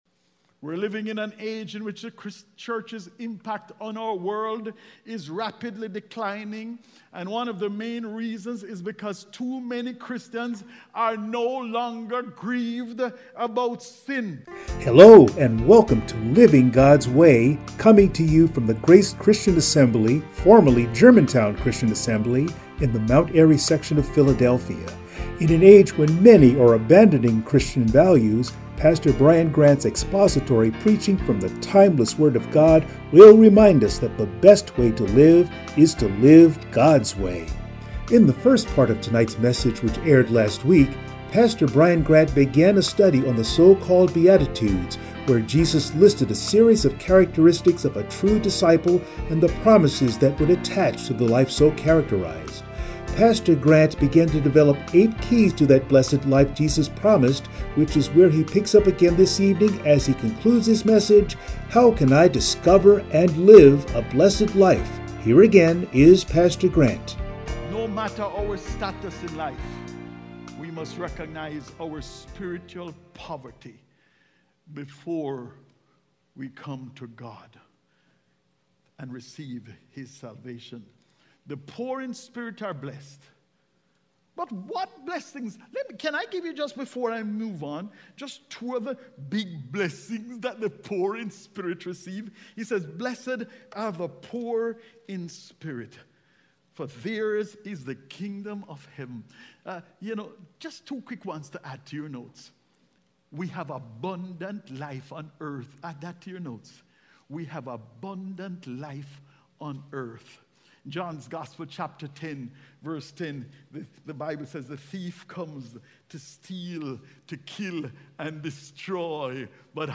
The Beatitudes Passage: Matthew 5:1-16 Service Type: Sunday Morning